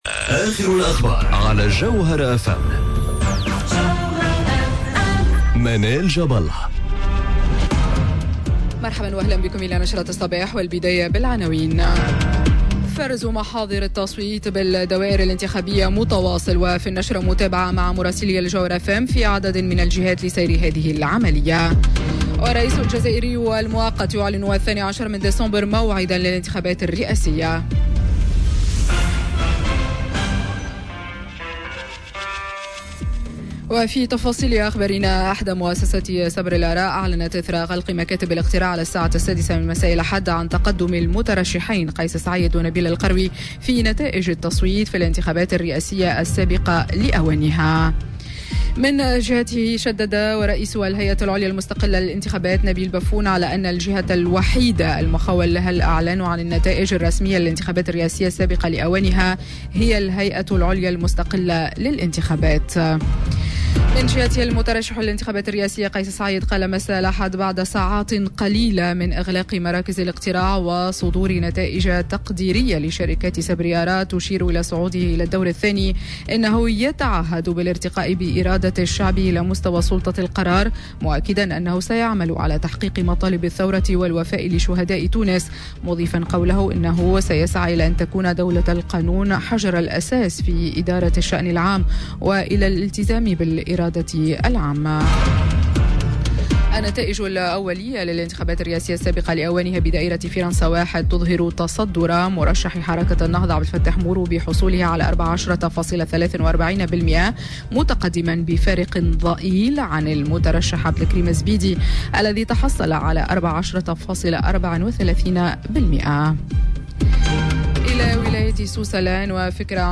نشرة أخبار السابعة صباحا ليوم الإثنين 16 سبتمبر 2019